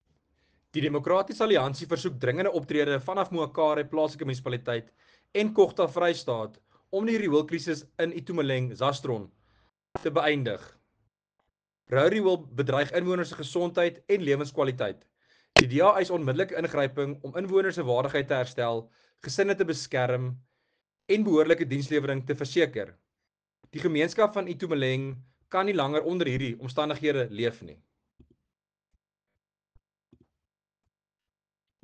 Afrikaans soundbite by Werner Pretorius MPL, and